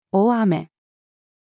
大雨-female.mp3